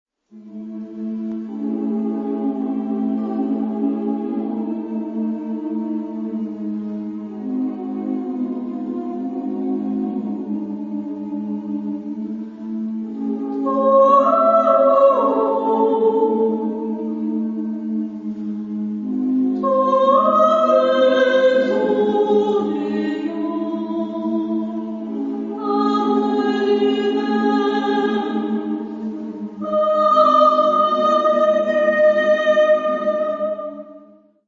Género/Estilo/Forma: Profano ; Poema ; contemporáneo
Tipo de formación coral: SSSMMMAA  (7 voces Coro femenino )
Tonalidad : la mayor